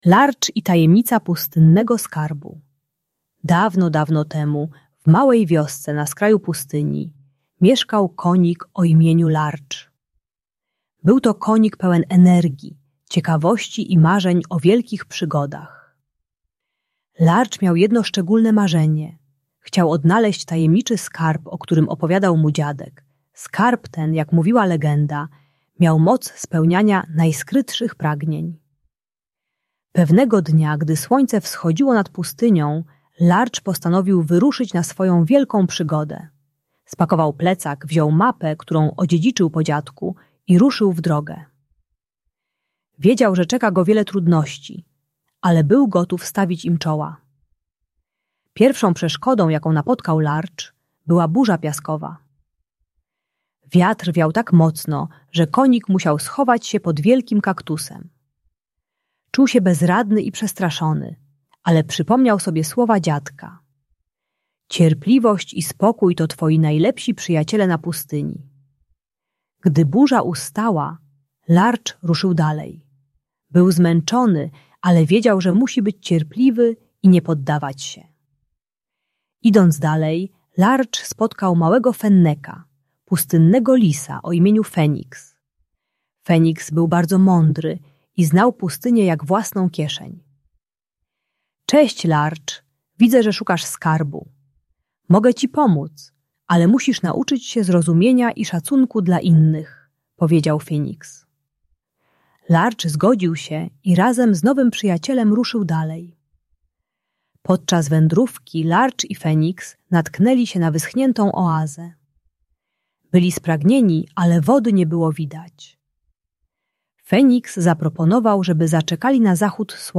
Lardż i Tajemnica Pustynnego Skarbu - Agresja do rodziców | Audiobajka